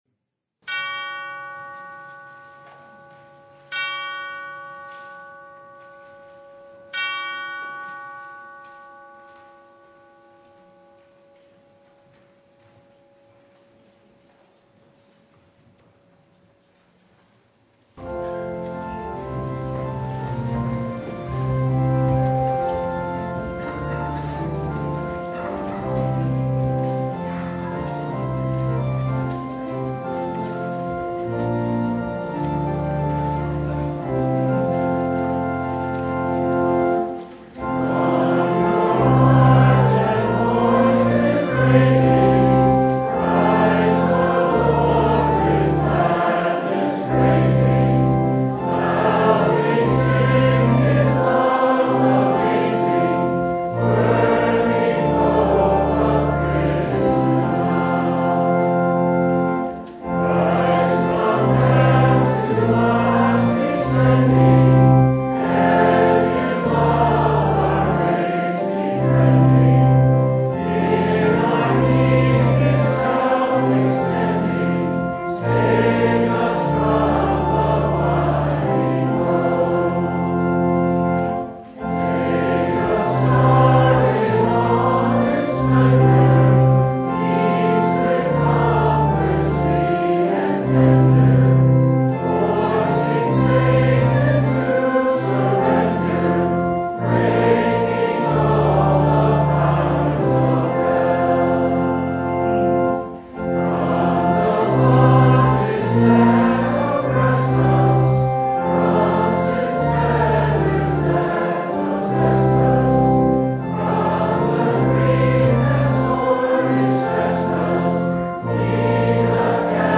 Christmas Eve 2021 Vespers (audio recording)
Luke 2:1-14 Service Type: Christmas Eve Vespers With the birth of this Child
Download Files Bulletin Topics: Full Service « Advent Vespers 2021 Ruth Chapter 4 – The Solution What Child Is This?